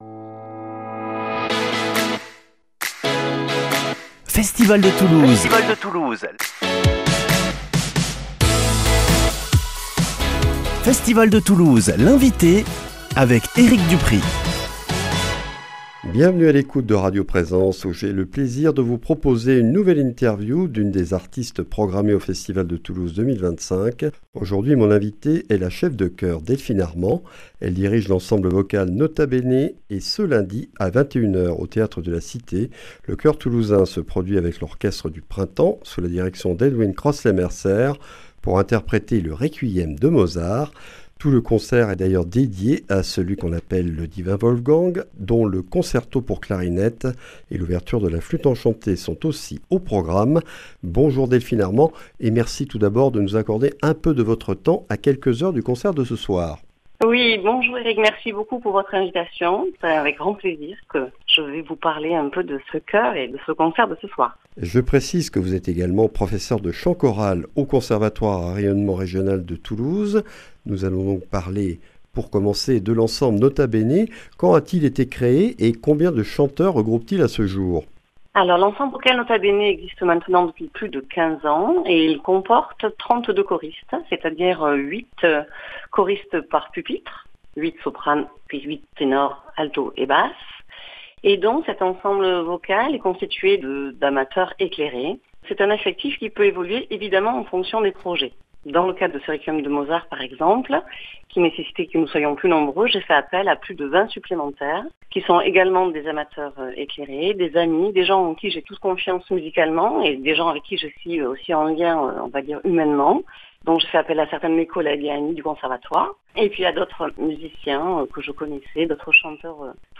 Festival de Toulouse - Interviews